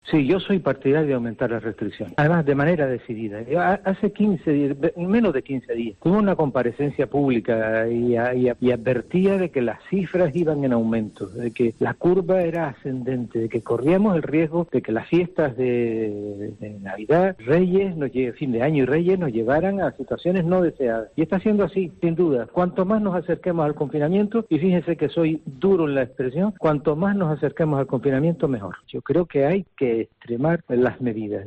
Antonio Morales, presidente del Cabildo de Gran Canaria
Antonio Morales, presidente del Cabildo de Gran Canaria ha asegurado en los micrófonos de COPE Canarias que es partidario de aumentar las restricciones en Gran Canaria para frenar la tendencia en el aumento del número de casos de coronavirus que se está produciendo tras la Navidad.